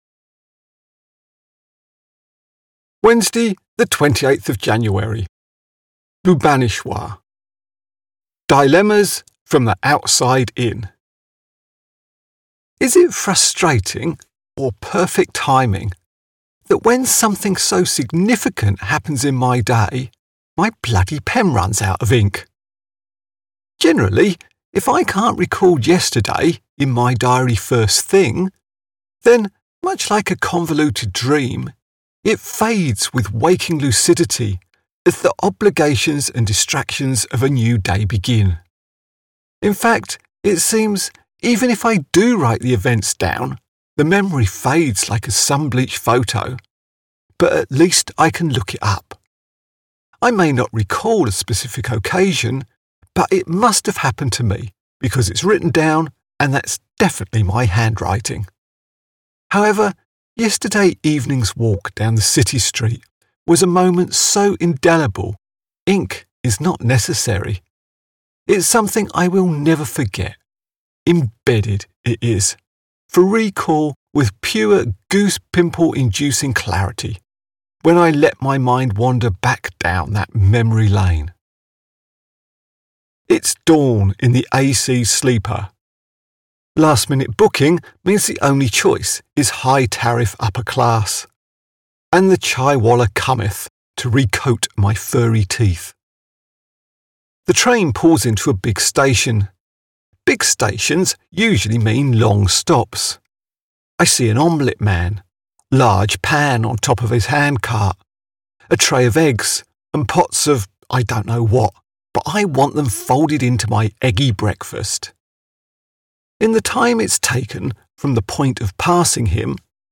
Here is a chapter from the audio book https